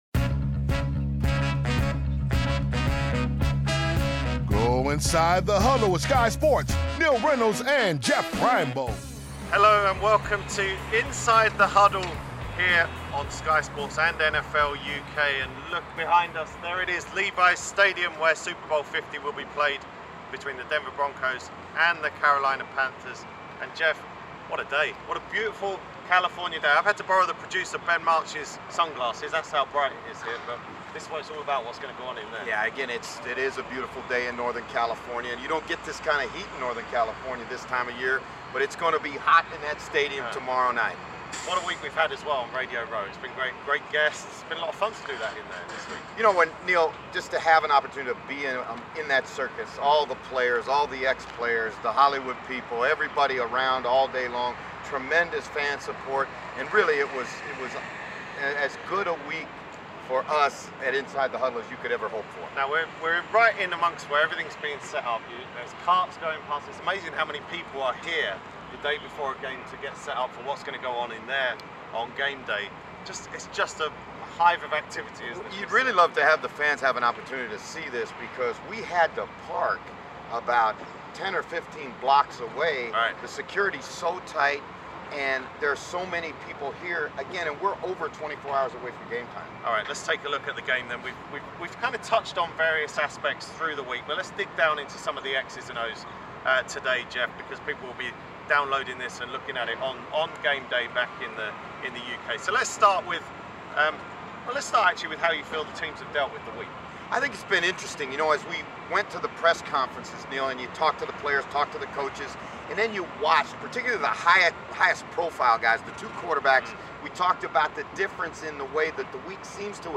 record their podcast outside Levis Stadium the day before the big game - Super Bowl 50 between the Carolina Panthers and the Denver Broncos.